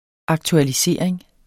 Udtale [ ɑgtualiˈseˀɐ̯eŋ ]